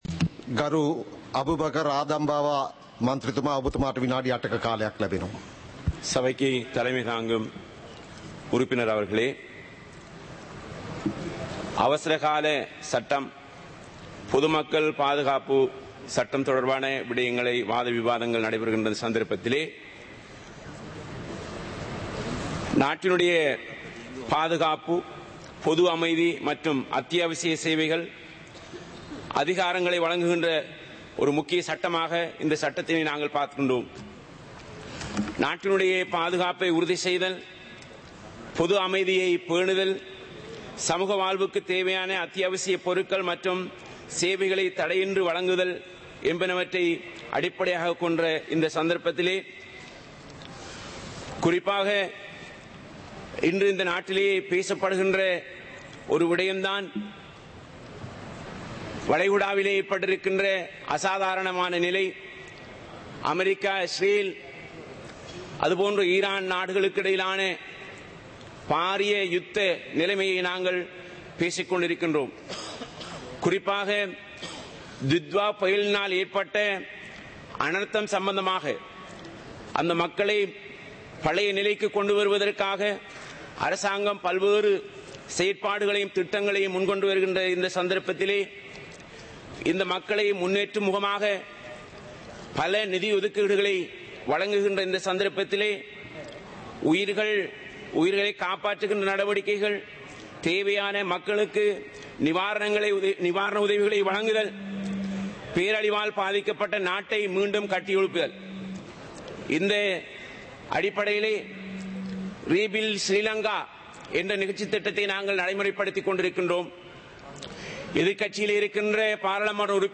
Proceedings of the House (2026-03-06)
Parliament Live - Recorded